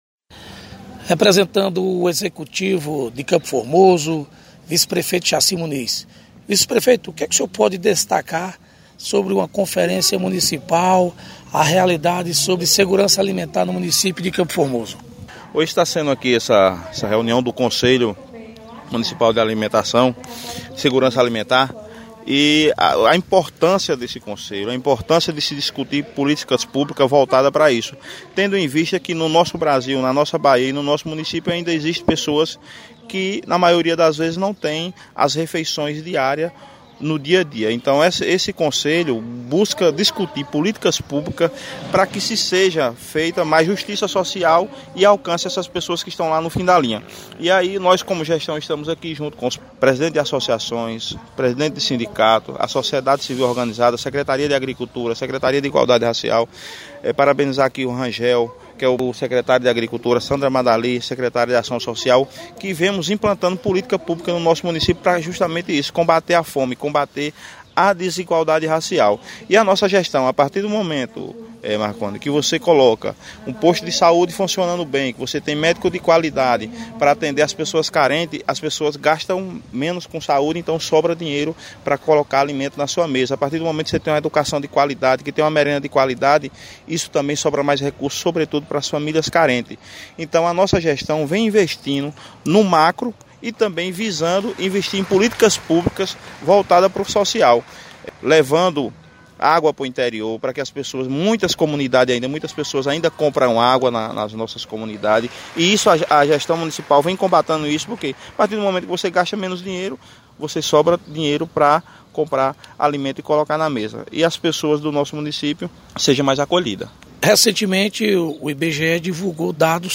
Vice prefeito Jaci Muniz, representando o Executivo, destaca como relevante a discussão sobre políticas públicas